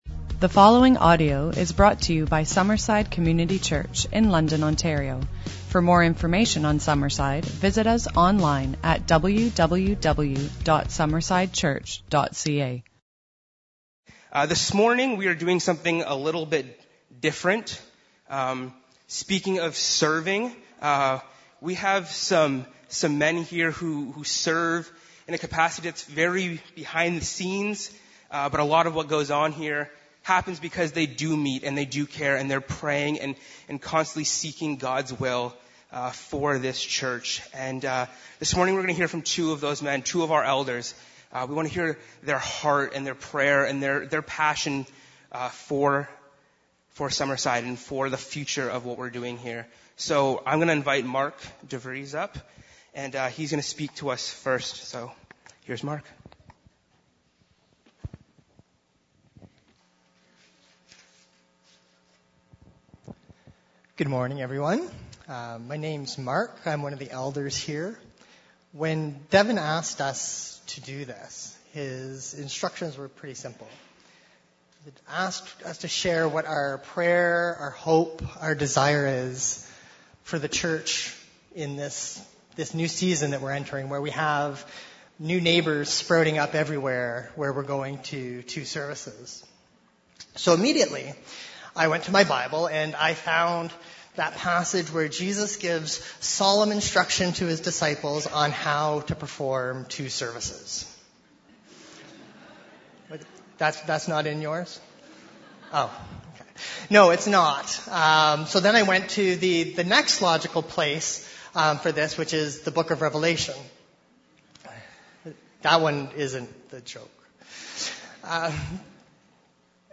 Today, two of our Elders will be sharing.